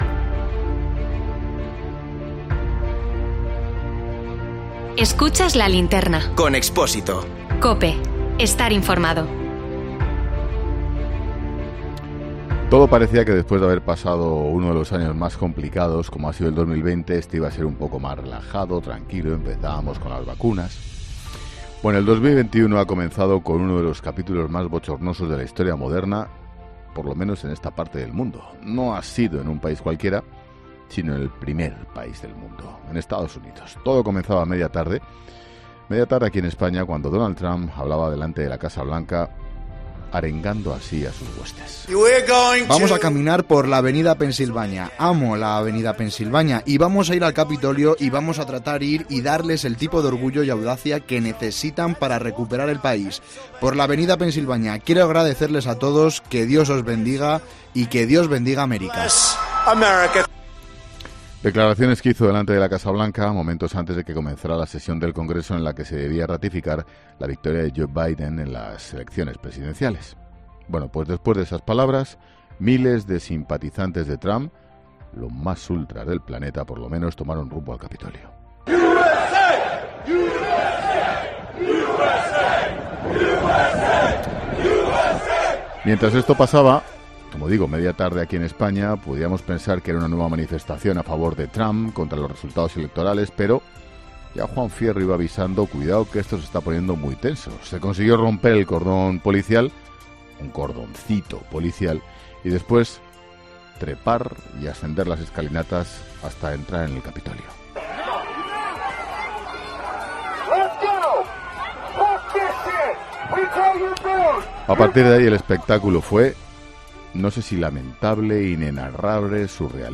En nuestro tema del día, analizamos con nuestros corresponsales y expertos las consecuencias de uno de los incidentes más bochornosos de la historia de...
Es el sonido del disparo que acabó con la vida de una de las asaltantes.